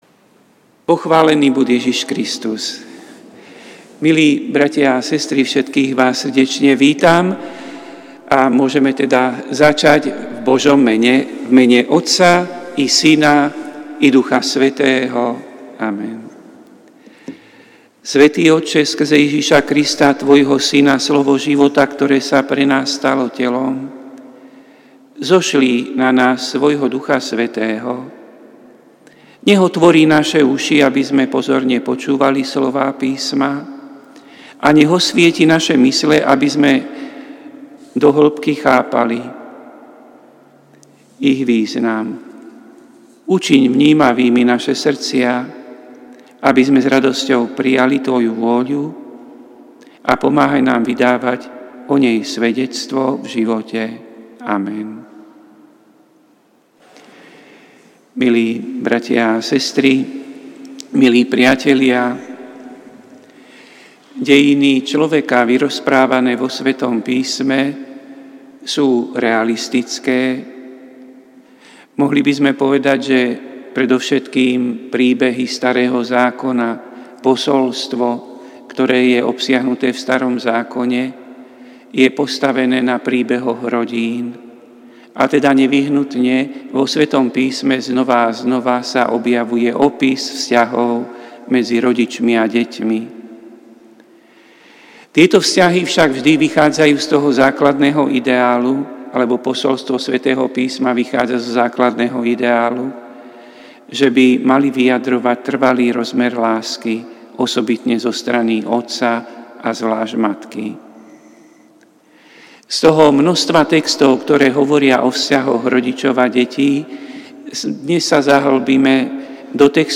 Prinášame plný text a audio záznam z Lectio divina, ktoré odznelo v Katedrále sv. Martina 13. marca 2024.